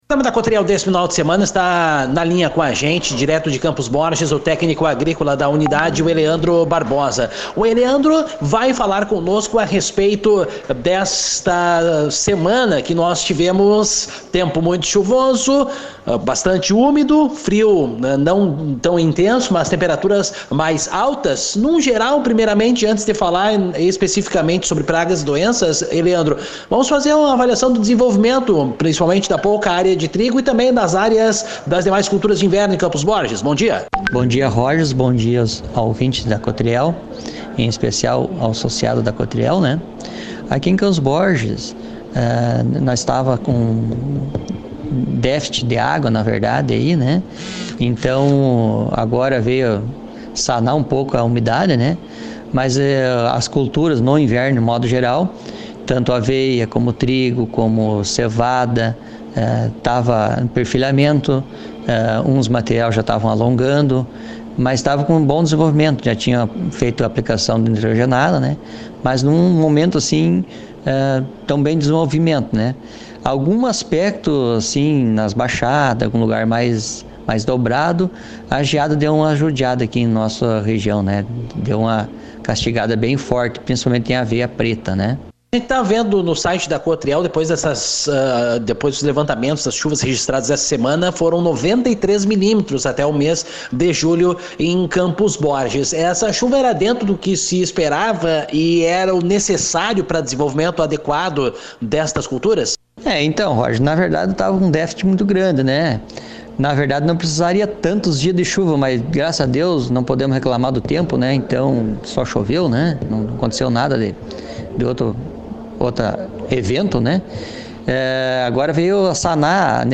aqui a entrevista.